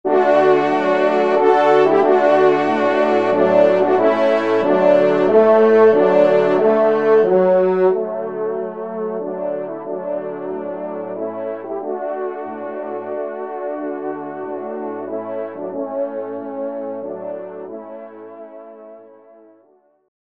Genre : Fantaisie Liturgique pour quatre trompes
ENSEMBLE